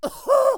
traf_damage4.wav